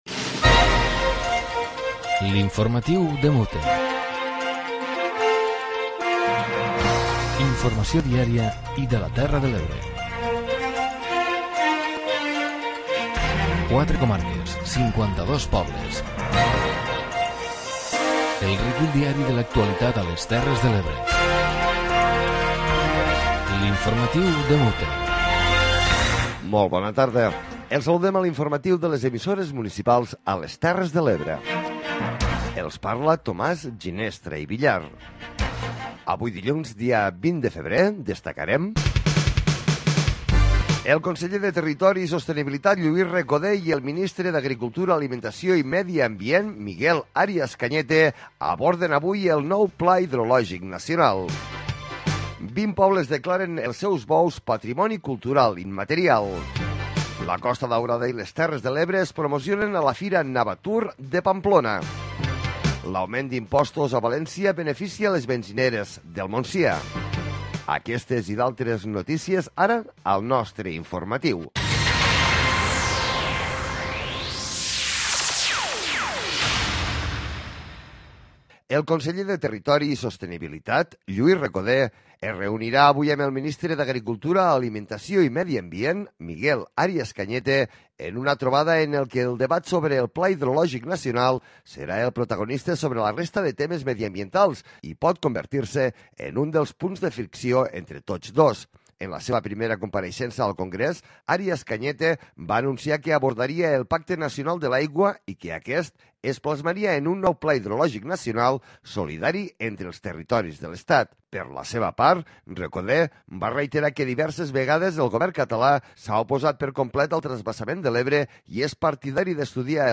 Informatiu comarcal diari de les emissores municipals de les Terres de l'Ebre.